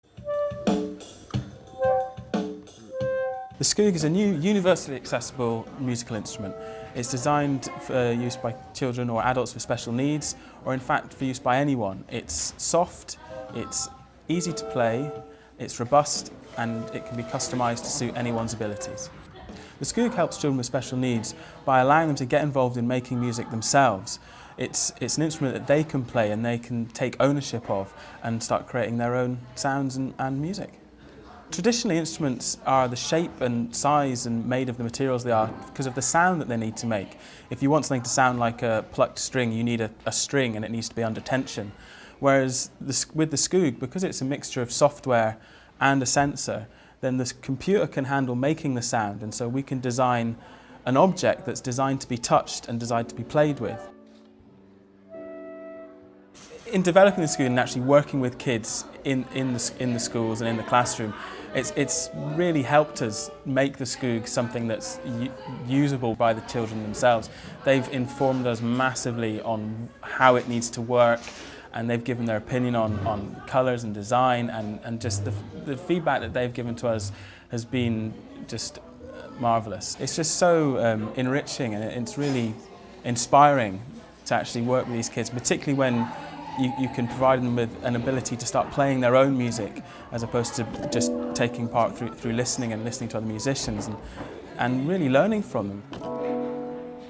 A New Instrument – Retell Lecture